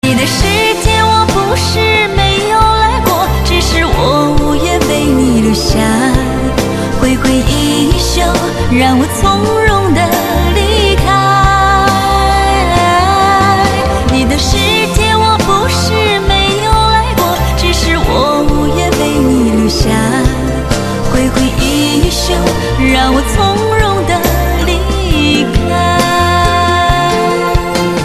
M4R铃声, MP3铃声, 华语歌曲 43 首发日期：2018-05-15 17:08 星期二